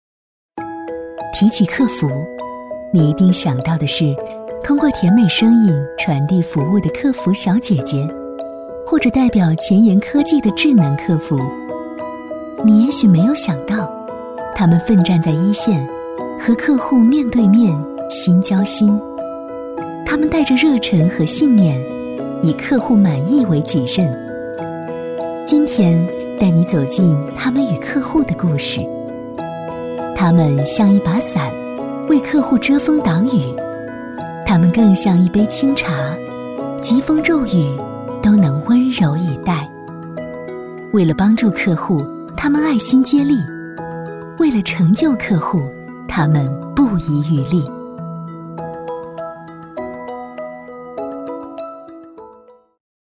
女35-旁白解说【普惠线下客服-亲切温暖】
女35-磁性甜美 温和抒情
女35-旁白解说【普惠线下客服-亲切温暖】.mp3